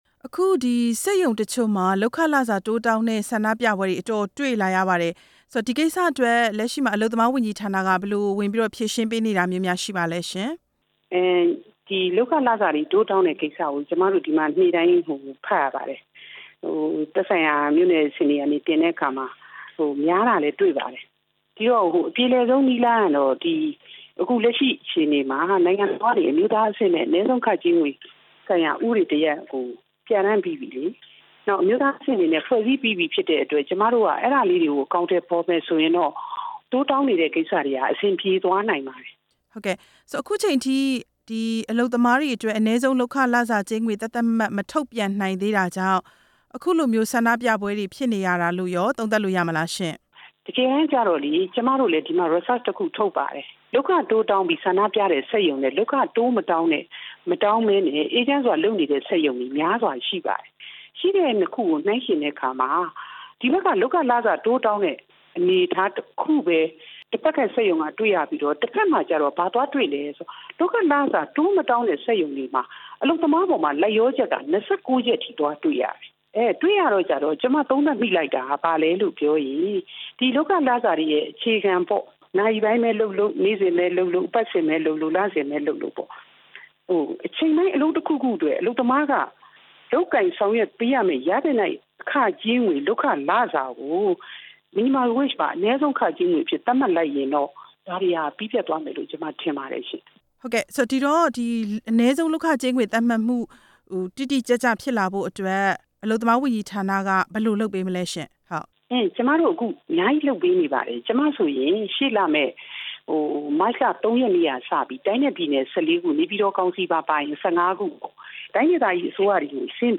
ဒုဝန်ကြီး ဒေါ်ဝင်းမော်ထွန်းနဲ့ မေးမြန်းချက်